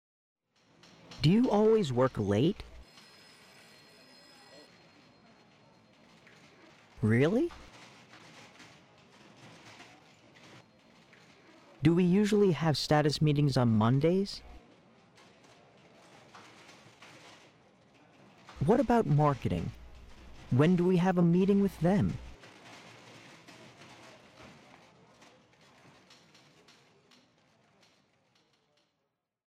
Now listen again and do yourself the secretary’s part as many times as necessary for a good performance.